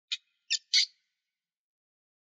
PixelPerfectionCE/assets/minecraft/sounds/mob/rabbit/idle1.ogg at c56acfee49e7e1bcd779741dcd49ed8fe864c119